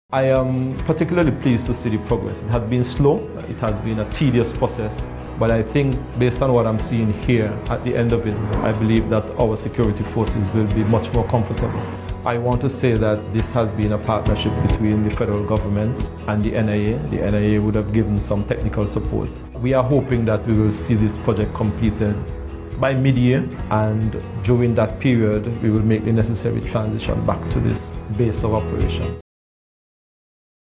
Minister of Public Works-Nevis, the Hon. Spencer Brand, speaking about the Charlestown Police Station renovation project on March 31st.